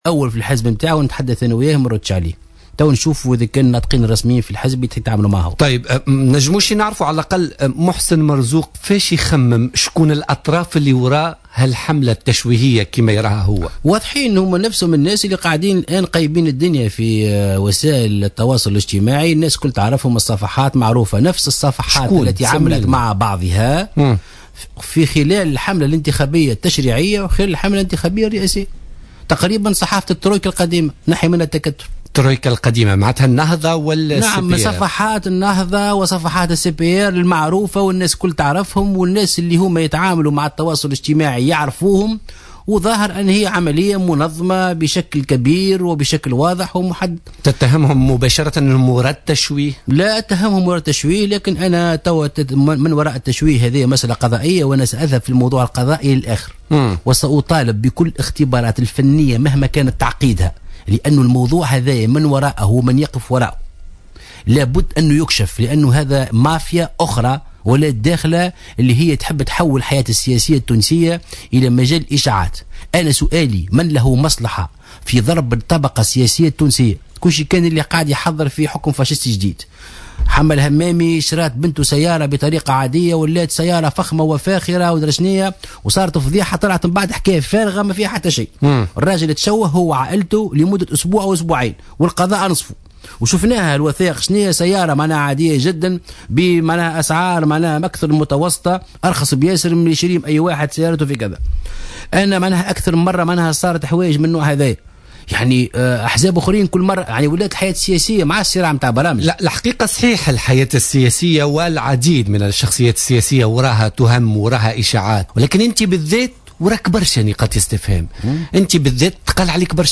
رفض محسن مرزوق رئيس حركة مشروع تونس ضيف بوليتيكا اليوم الإثنين 11 أفريل 2016 الرد على تصريحات عدنان منصر بخصوص اتهامه بالتورط في فضيحة "وثائق بنما" مؤكدا أنه لن يرد إلا على المسؤول الأول في حزبه بإعتباره المسؤول الأول عن حزبه المشروع".